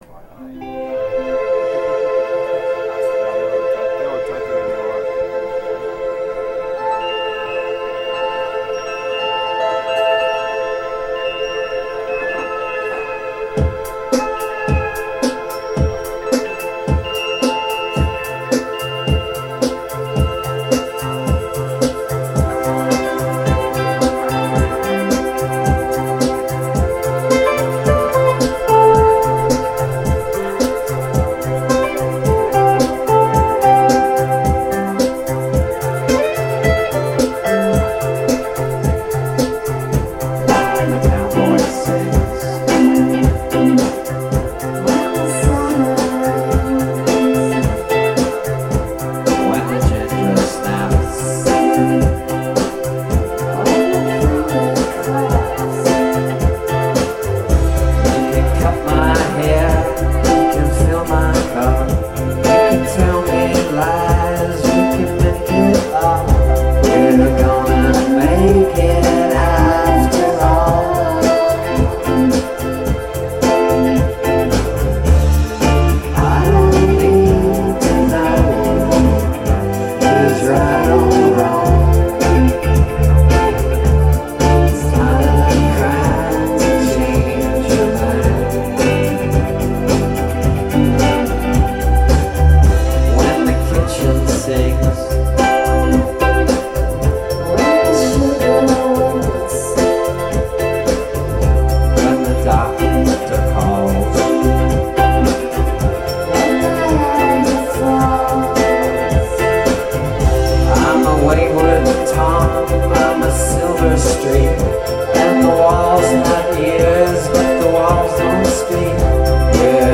The Village Underground, 24th June 2003
Village Underground, New York, NY, USA